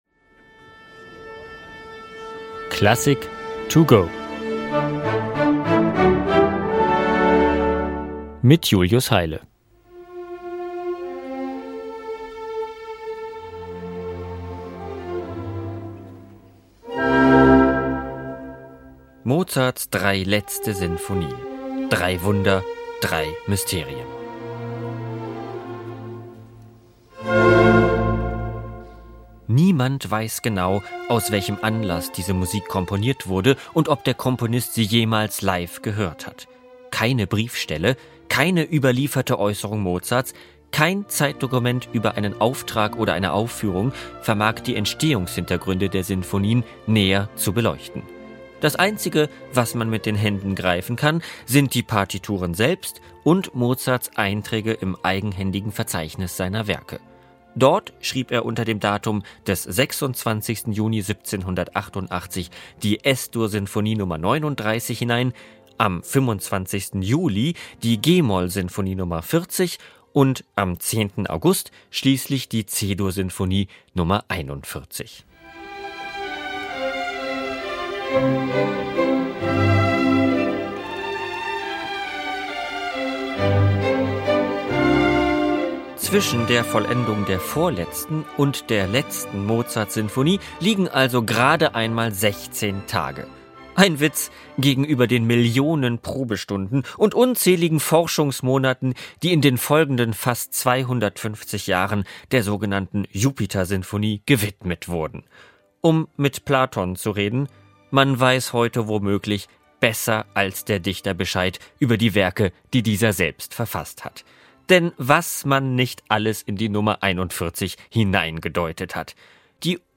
Kurzeinführung für unterwegs